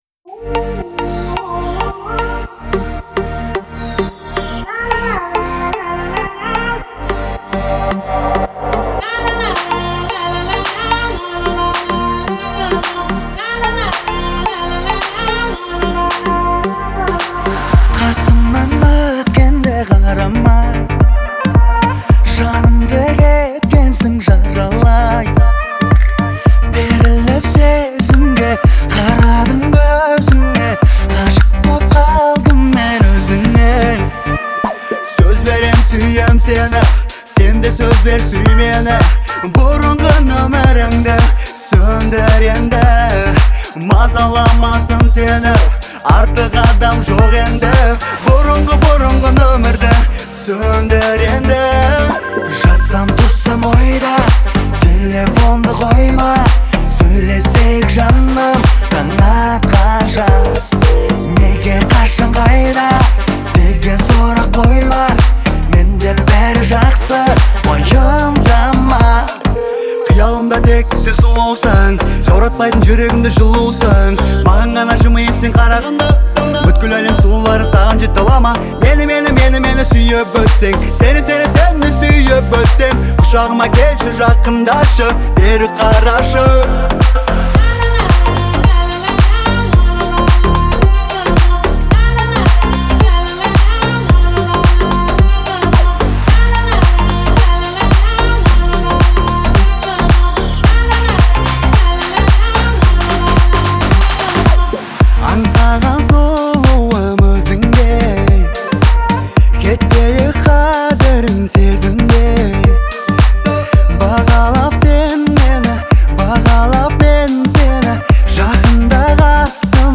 это динамичная композиция в жанре поп с элементами R&B